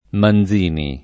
[ man z& ni ]